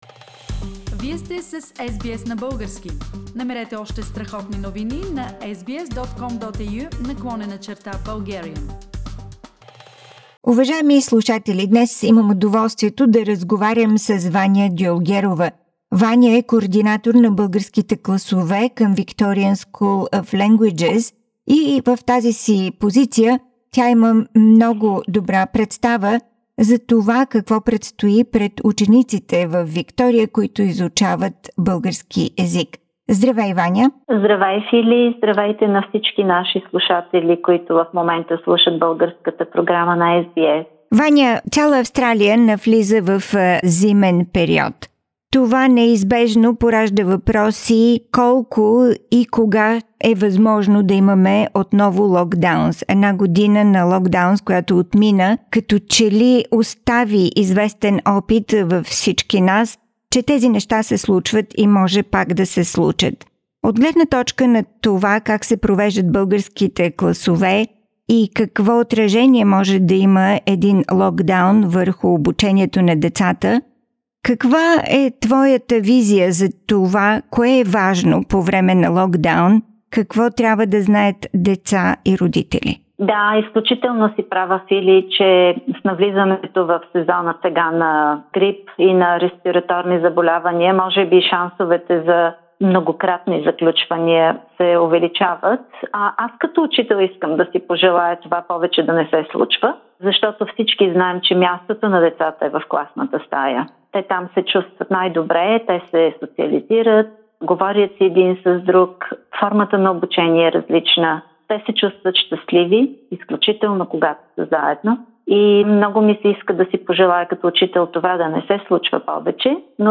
Какви са последствията от дистанционното обучение и локдауните върху учениците от българското училище в Мелбърн? Разговор